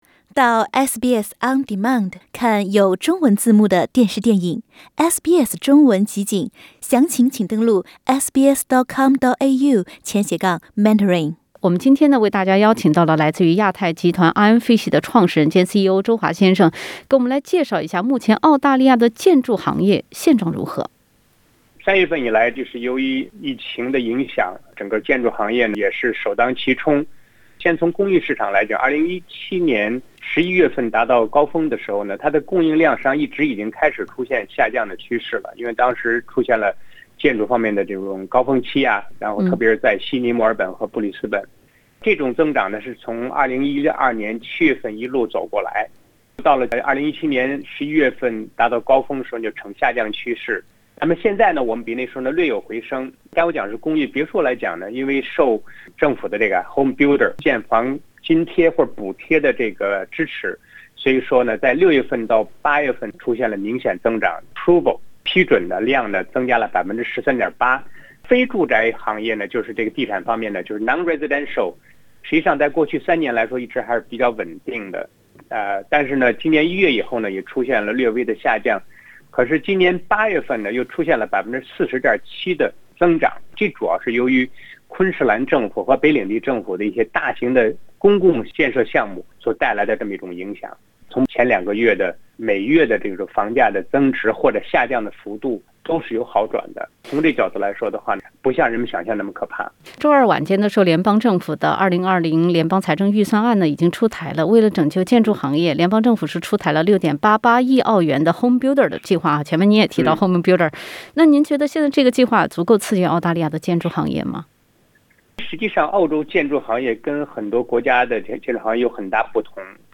（点击上方图片收听录音采访） READ MORE 如何订阅SBS中文的newsletter？